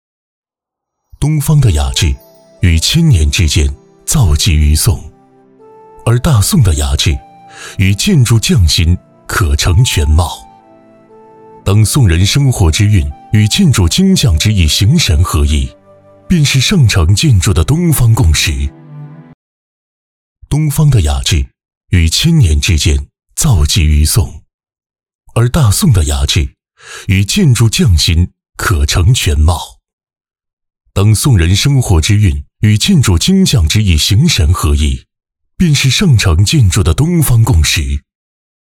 淘声配音网站创立于2003年，是一家面向全国以互联网为平台的专业网络配音服务机构，汇集了服务于央视及各省卫视的大量优秀配音播音人才，拥有优秀的专业配音师，拥有自己专业的常规录音棚、角色棚等。